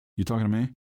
Download You Talking To Me Half Life sound effect for free.